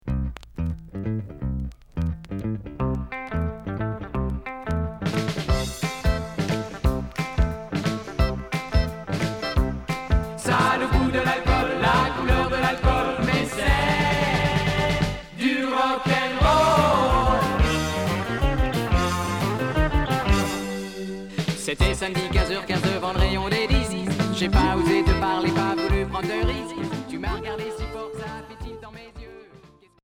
Rock Cinquième 45t retour à l'accueil